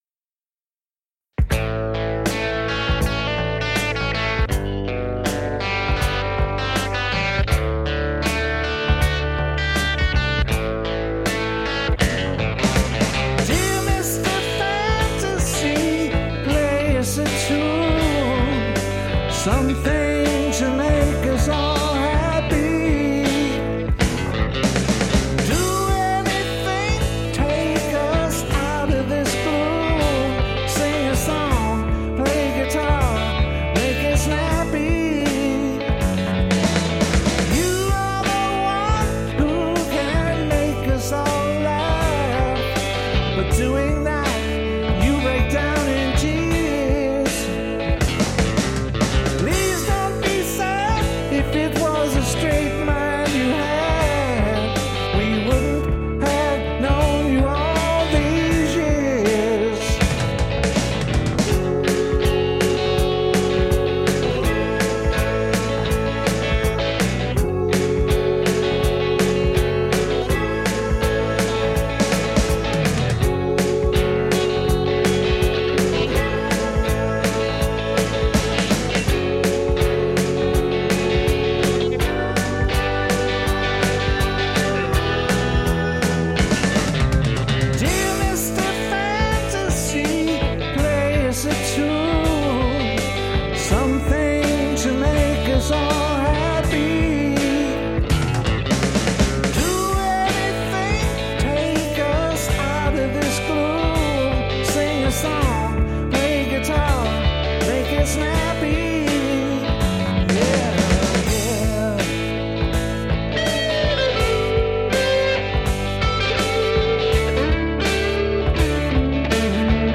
- Classic Rock Covers -
Psychedelic